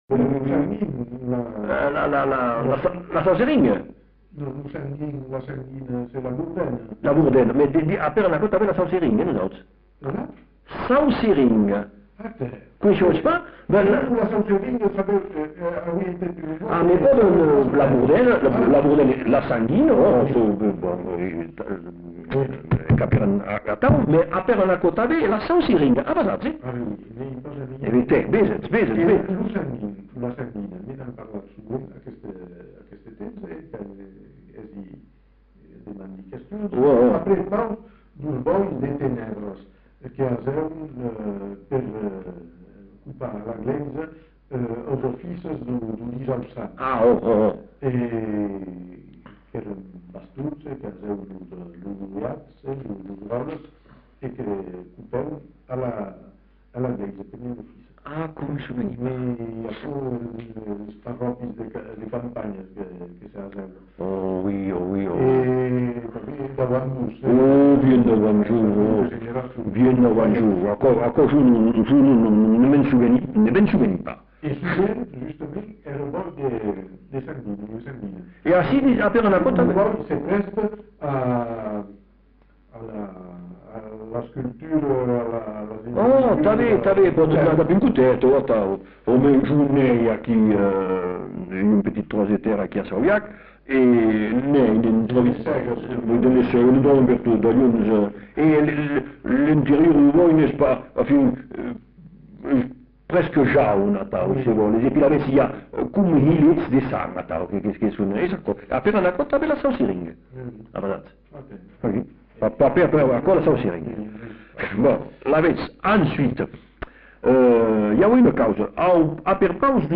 Genre : témoignage thématique
[enquêtes sonores]